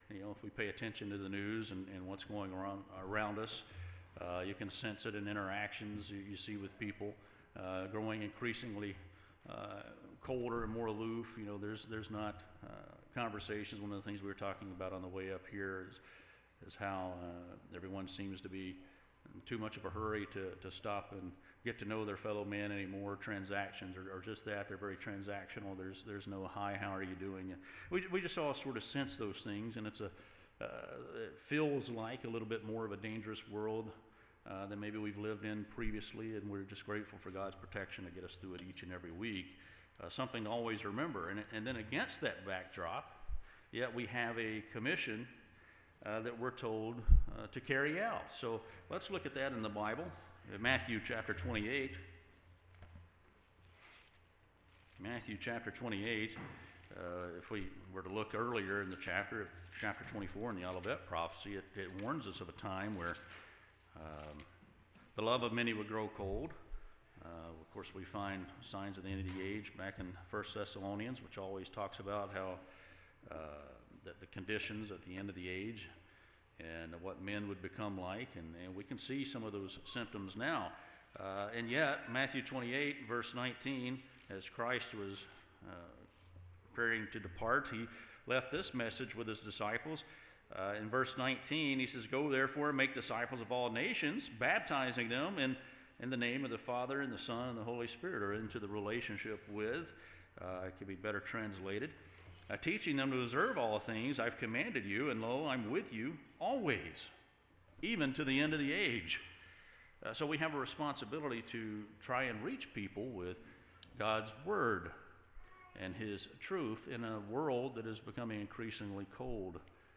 Given in Jacksonville, FL